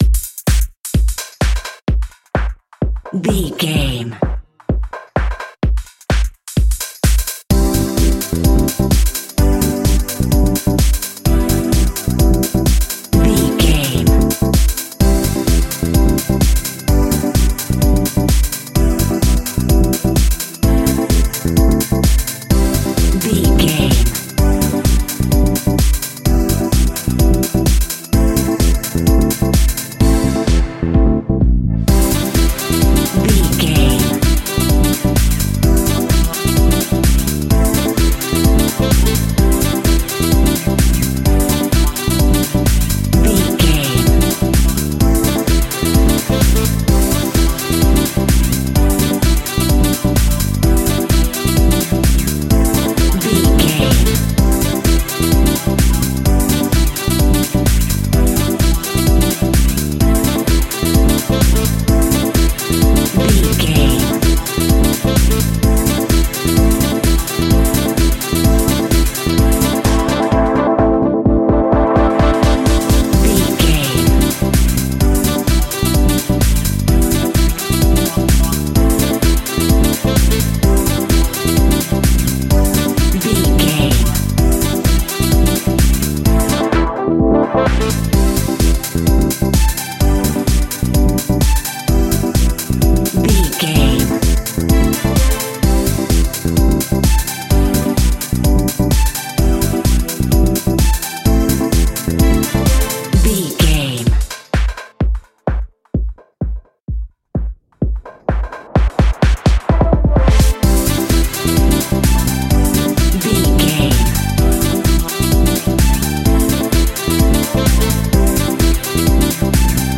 Dirty Sax Funky House.
Aeolian/Minor
groovy
uplifting
driving
energetic
bass guitar
drums
strings
saxophone
piano
electric piano
funky house
nu disco
upbeat
instrumentals